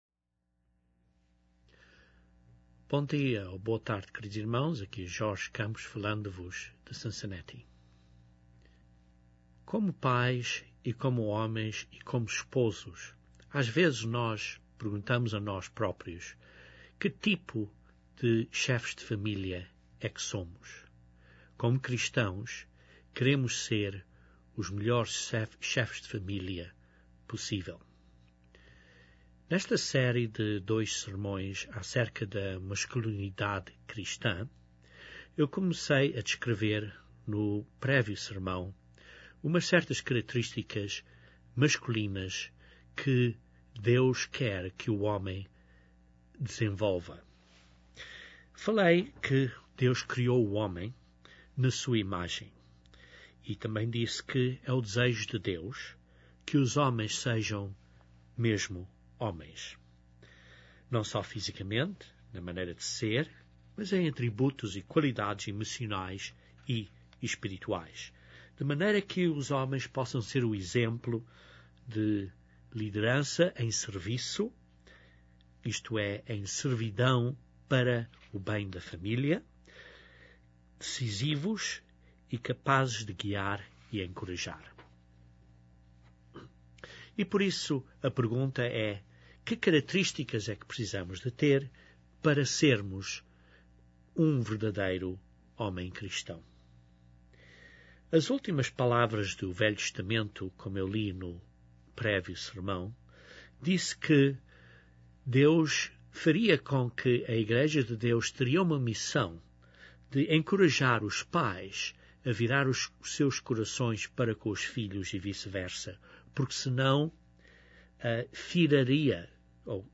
O homem Cristão precisa de navegar este caminho apertado. Estes 2 sermões dão vários princípios bíblicos para um verdadeiro homem Cristão.